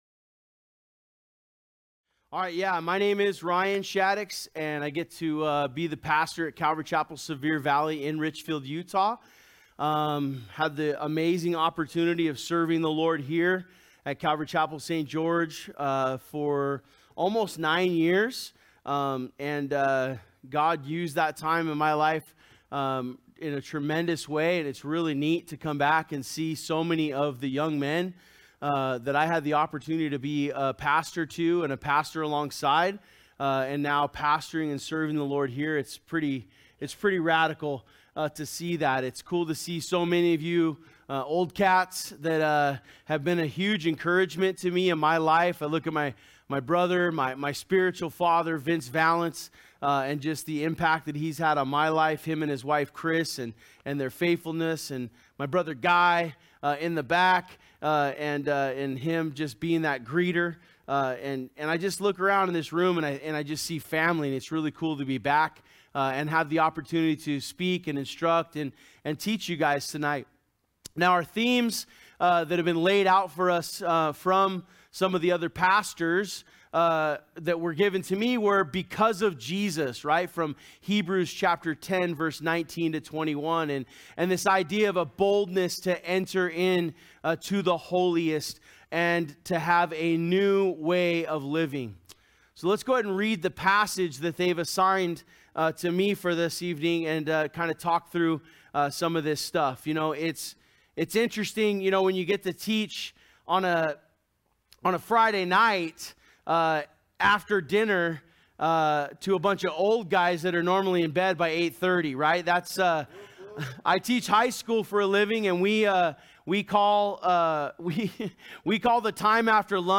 From Series: "Men's Conference 2023"